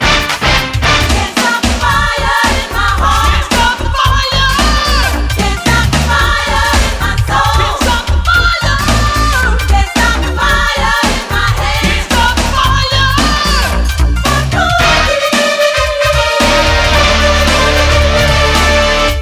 • Качество: 248, Stereo
саундтрек